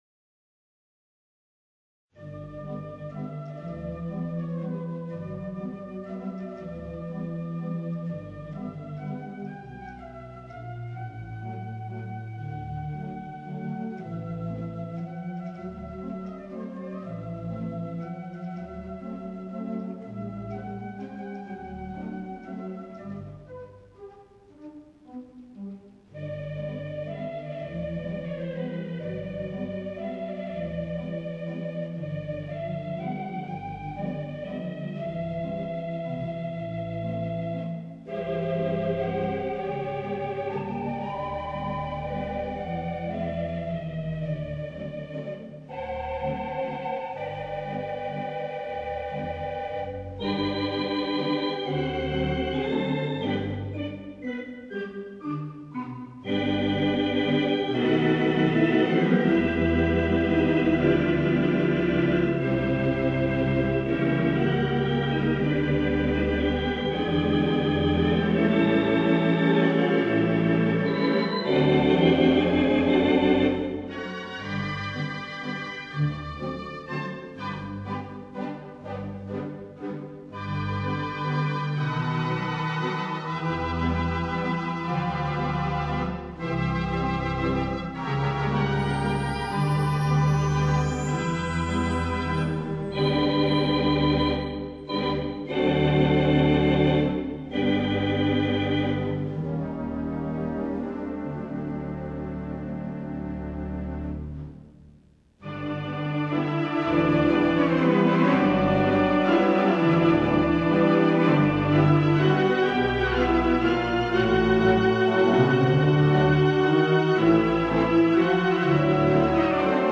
Theatre Pipe Organ